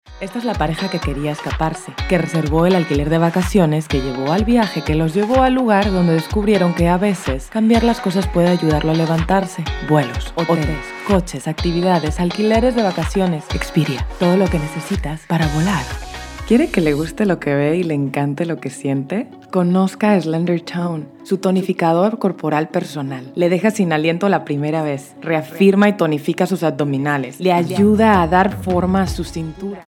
Versatile, naturally mature, essentially deep, inspiring, exciting, creative, surprising voice!
Vocal age between 15 and 50 years.
MEX SPA COMMERCIAL.mp3